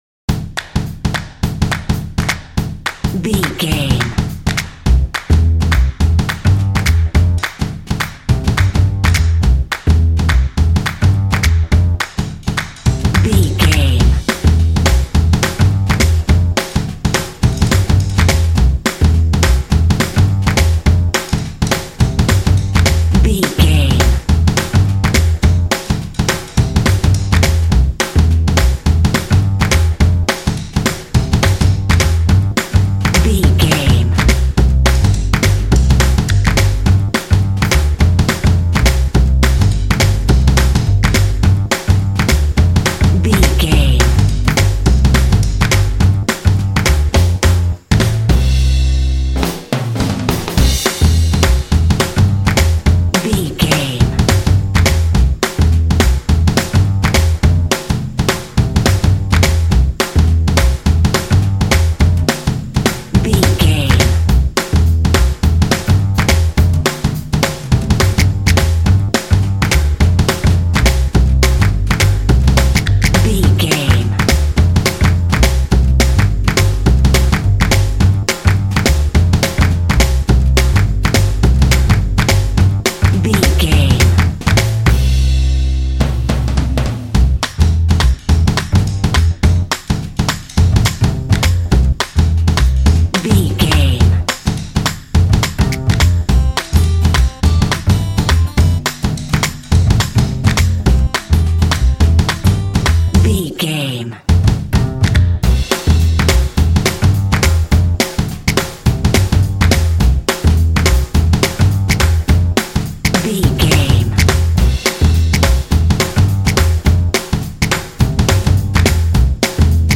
Uplifting
Ionian/Major
bouncy
energetic
groovy
drums
percussion
double bass
pop
rock
contemporary underscore